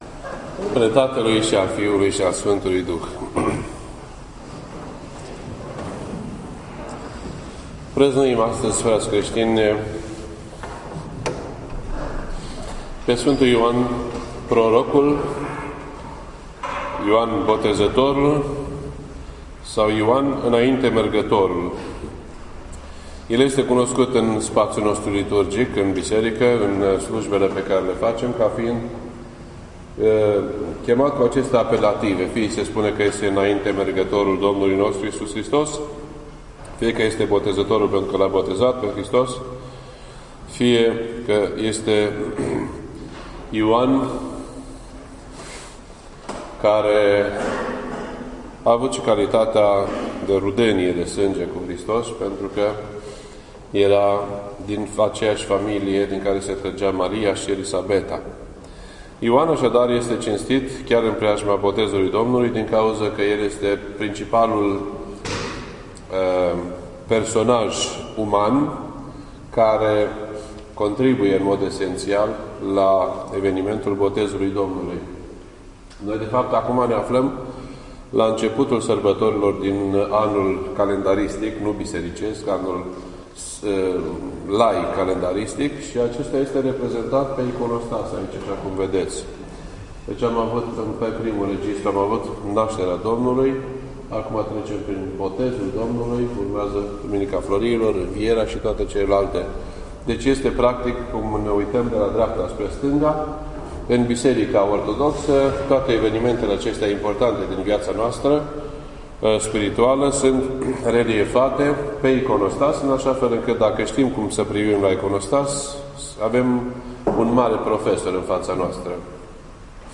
This entry was posted on Thursday, January 7th, 2016 at 11:31 AM and is filed under Predici ortodoxe in format audio.